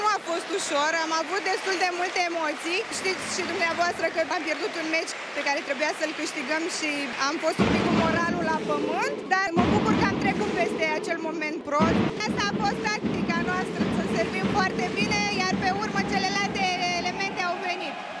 sursa audio: TVR